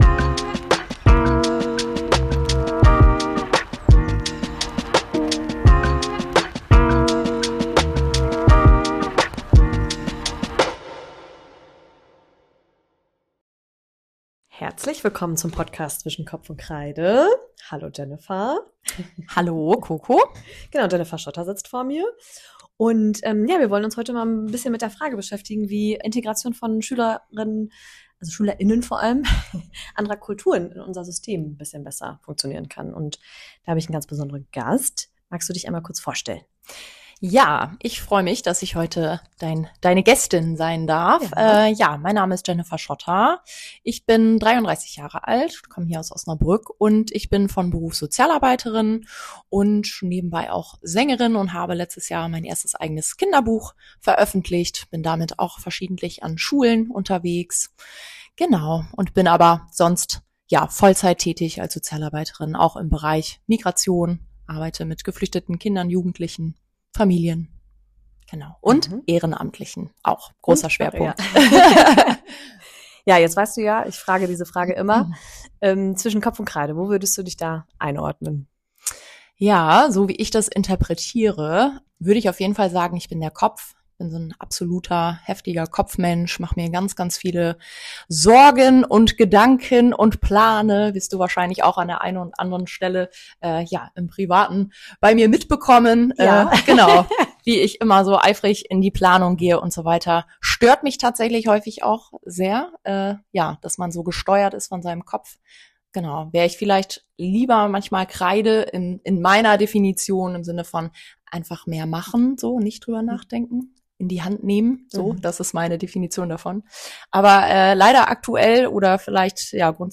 Ein Gespräch über strukturelle Herausforderungen, engagierte Lernpaten – und darüber, was Schule leisten kann (und was nicht).